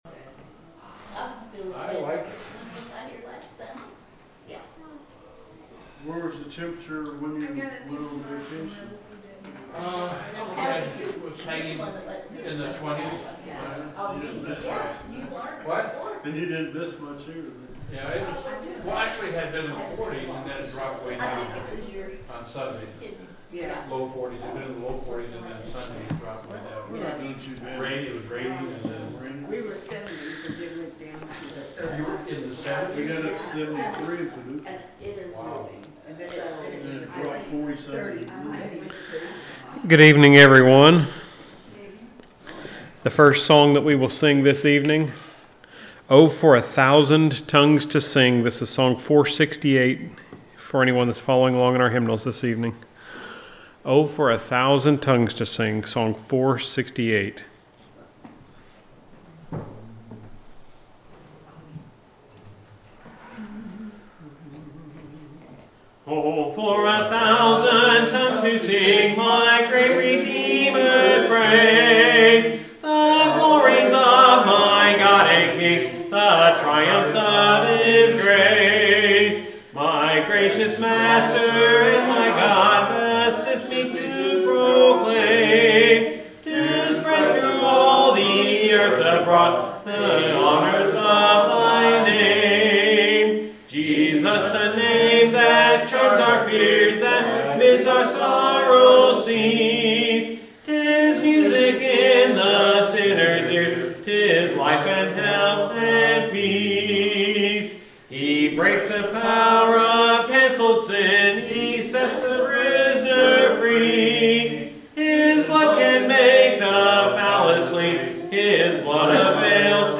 The sermon is from our live stream on 12/31/2025